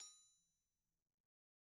Percussion
Anvil_Hit1_v1_Sum.wav